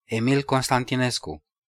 Emil Constantinescu (Romanian pronunciation: [eˈmil konstantiˈnesku]